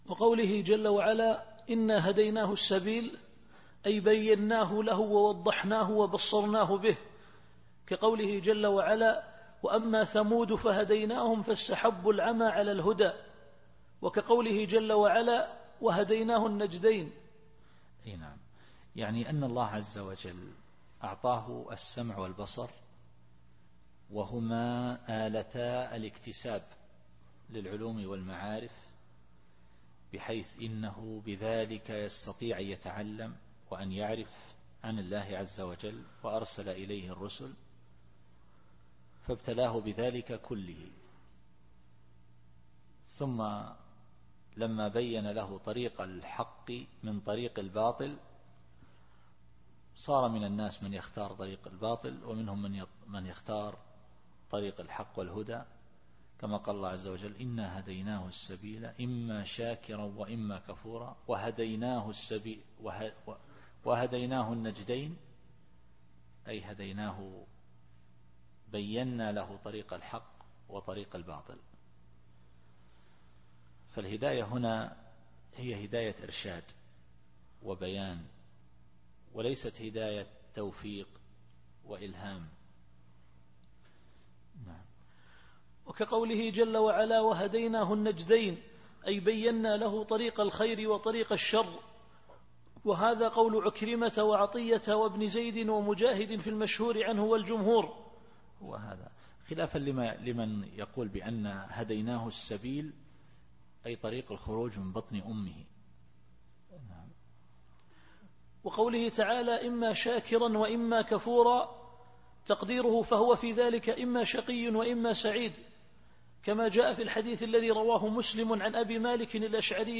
التفسير الصوتي [الإنسان / 3]